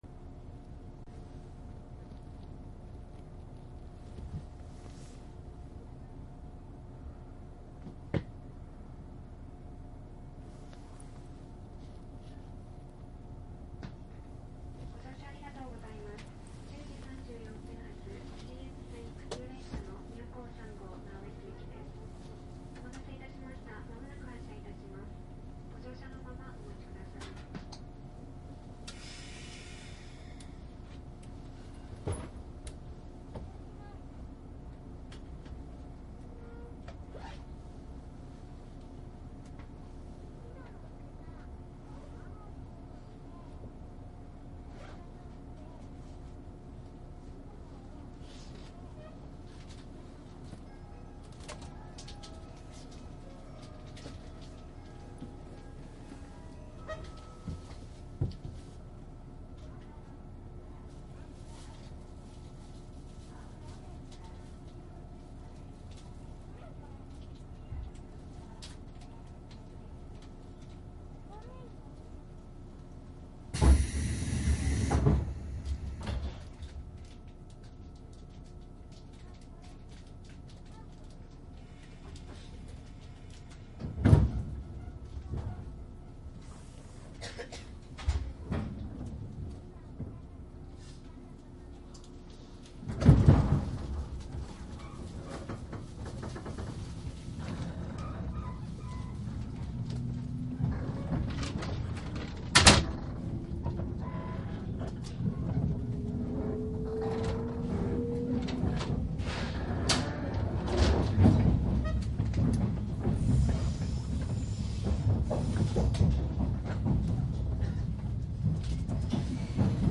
商品説明妙高3号「長野→直江津」鉄道走行音 ＣＤ
かなり以前に録音した189系 電車録音 ＣＤです。
デッキ仕切り扉、前半ガラガラで開けっ放しです。
乗客の増える後半の区間は閉にての録音になります。
サンプル音声 モハ189-32.mp3
マスター音源はデジタル44.1kHz16ビット（マイクＥＣＭ959）で、これを編集ソフトでＣＤに焼いたものです。